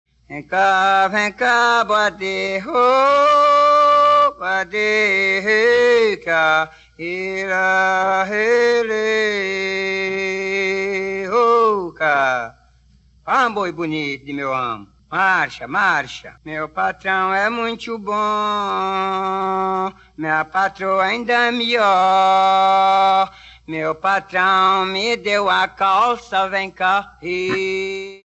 Aboio
Canto de trabalho utilizado para auxiliar os vaqueiros a tanger o gado. É formado por frases que geralmente finalizam com: Eh, boi!, Ei lá, boizinho! Além da voz, o vaqueiro também pode usar o berrante.
aboio.mp3